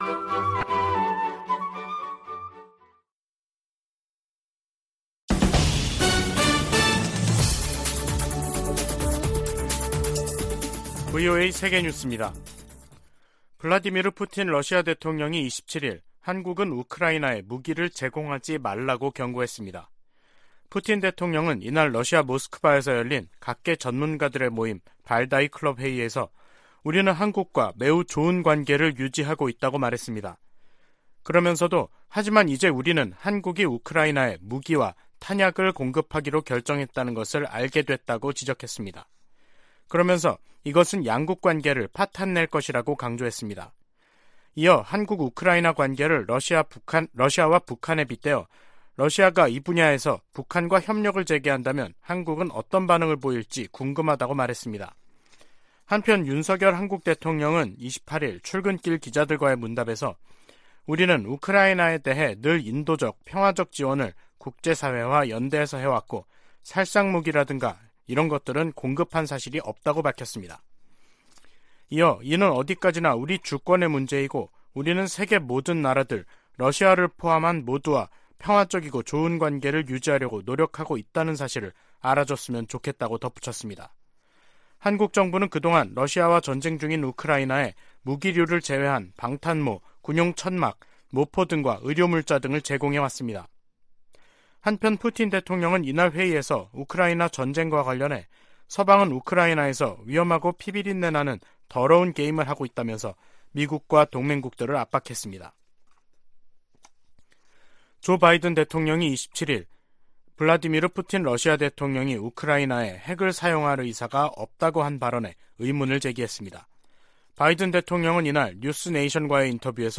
VOA 한국어 간판 뉴스 프로그램 '뉴스 투데이', 2022년 10월 28일 2부 방송입니다. 북한이 28일 동해상으로 단거리 탄도미사일(SRBM) 두 발을 발사했습니다. 북한이 7차 핵실험에 나선다면 국제사회가 엄중한 대응을 할 것이라고 백악관 고위관리가 밝혔습니다. 미 국방부는 북한 정권이 핵무기를 사용하고 살아남을 수 있는 시나리오는 없다고 경고했습니다.